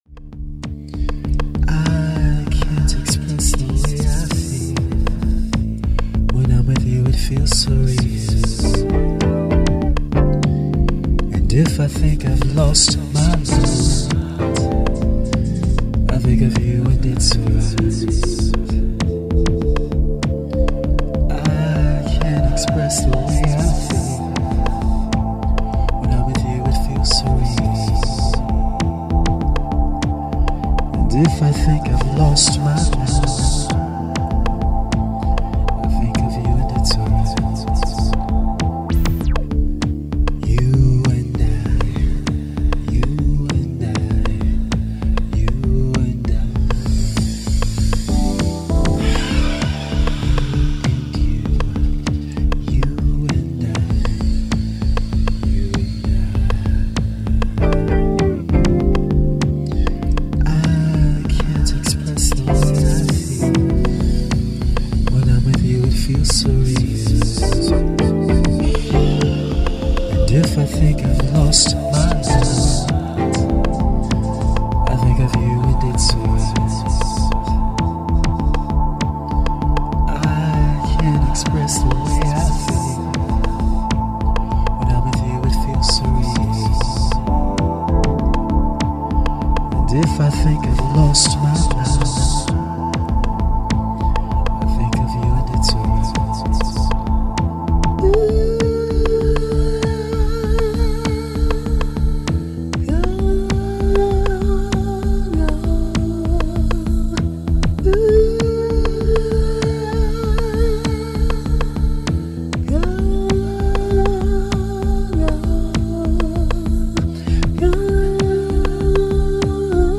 vocal-effect driven number